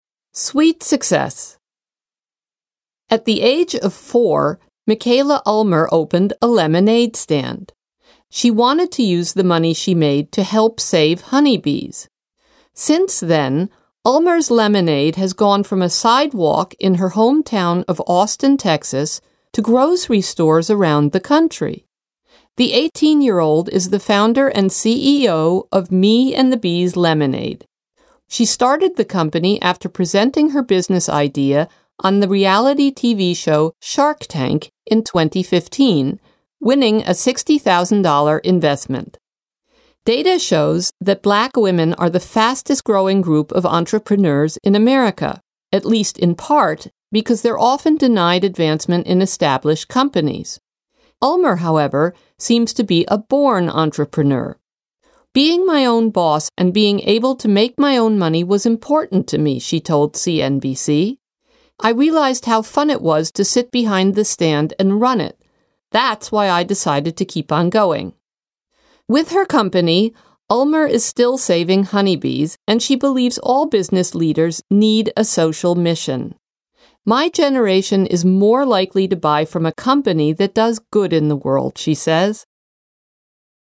US M